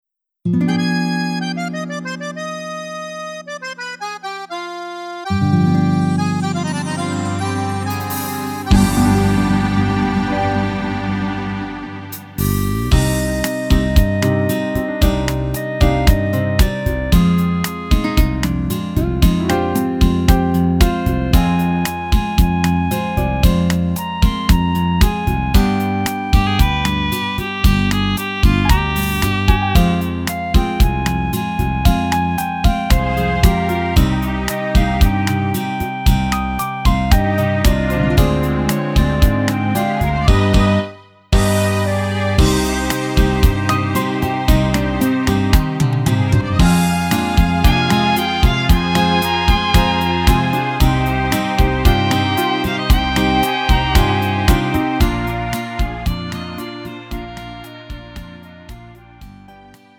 음정 원키 3:57
장르 구분 Lite MR